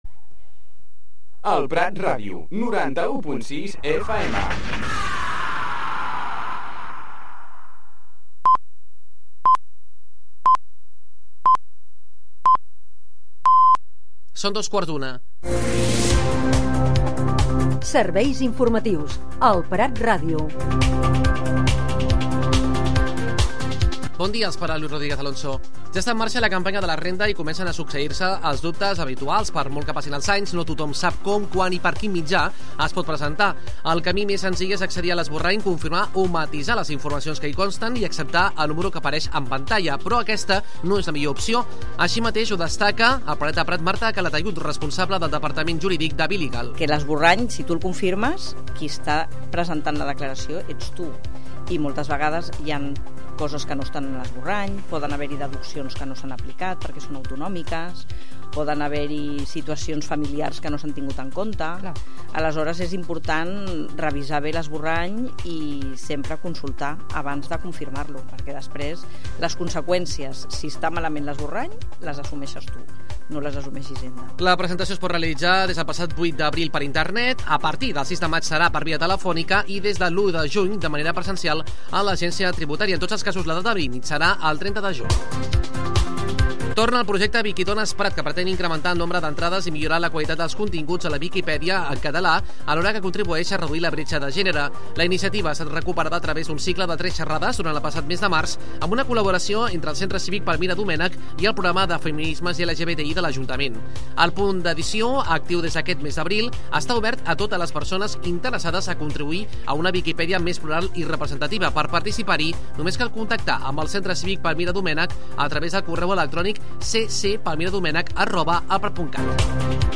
Espai informatiu d'elprat.ràdio, amb tota l'actualitat local i de proximitat.